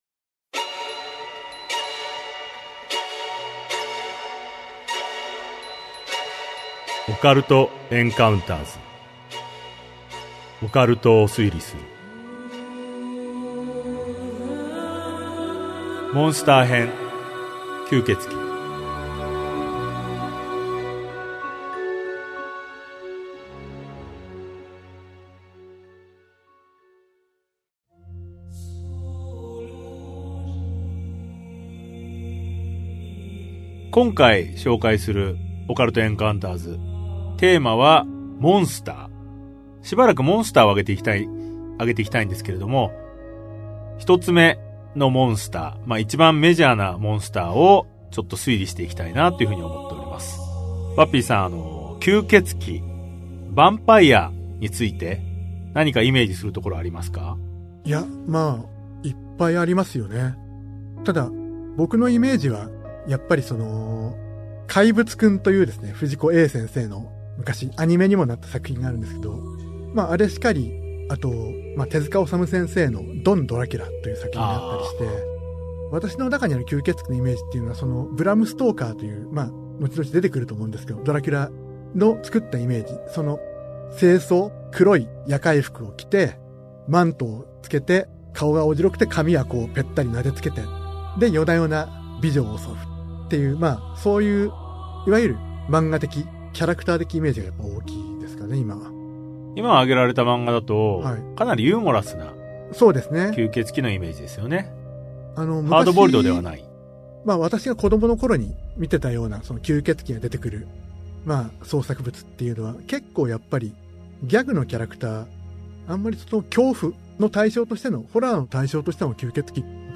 [オーディオブック] オカルト・エンカウンターズ オカルトを推理する Vol.12 モンスター編 吸血鬼